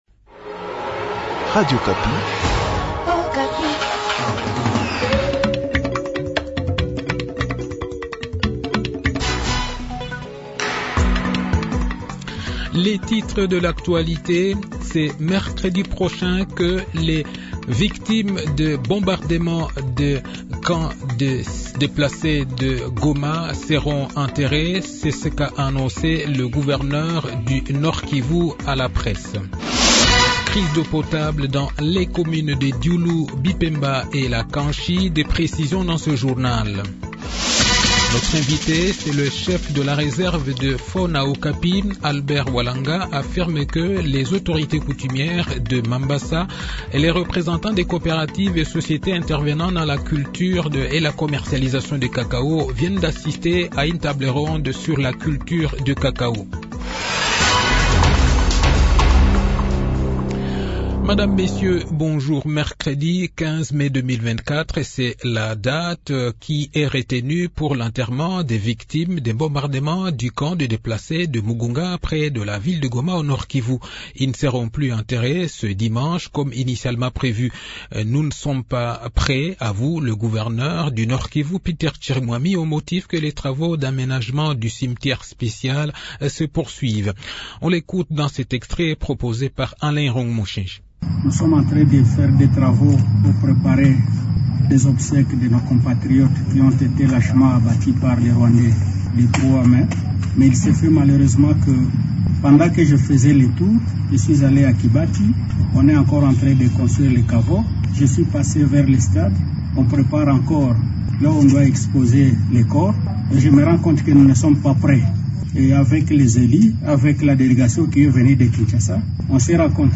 Journal de 15 h